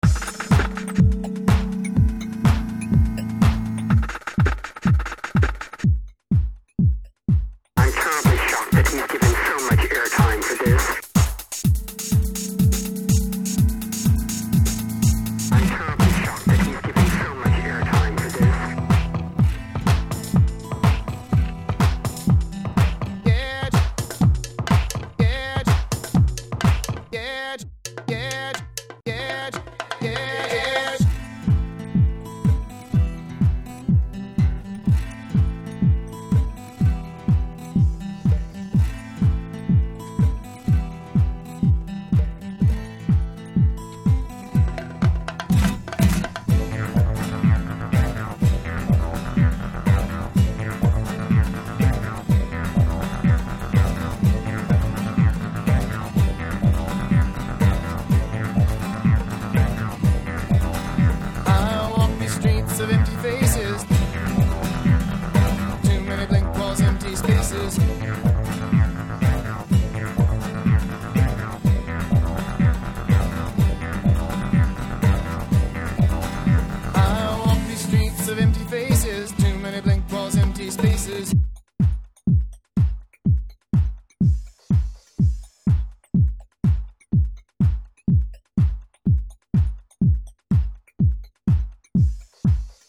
techno/dance mix